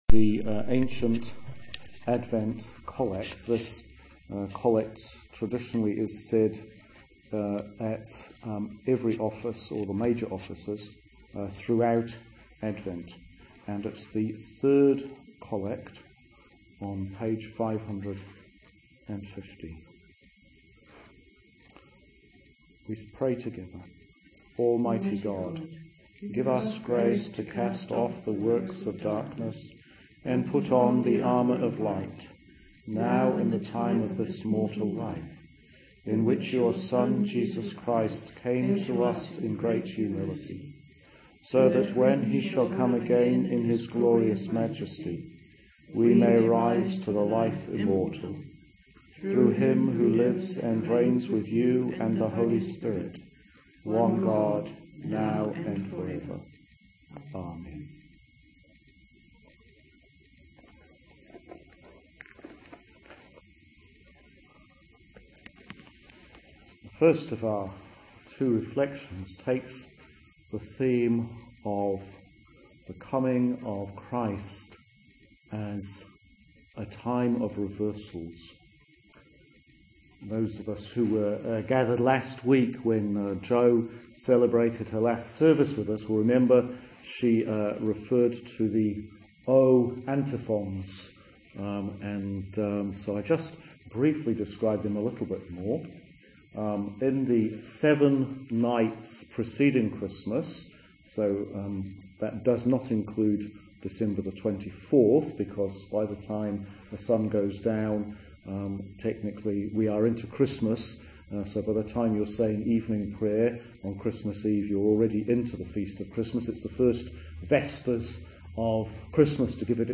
Please note, there are several periods of silence in this recording